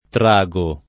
trago [ tr #g o ]